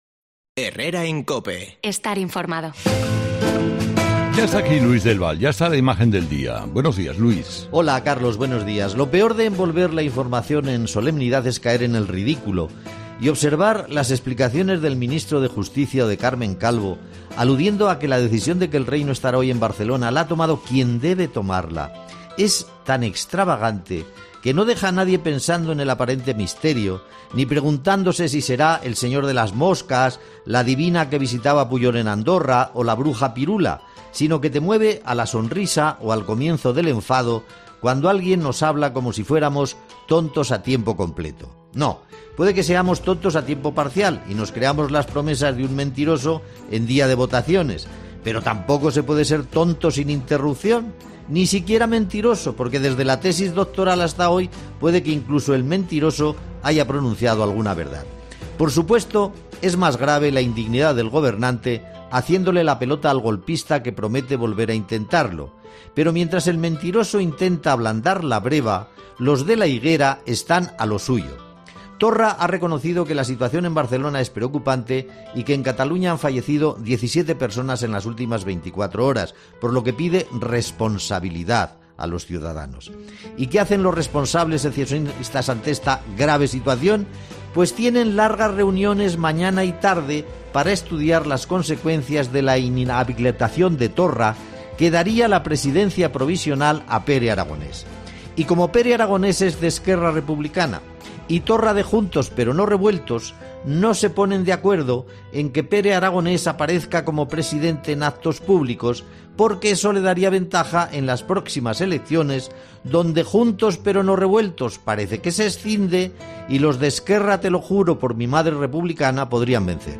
Ya puedes escuchar el comentario de Luis del Val hoy en 'Herrera en COPE'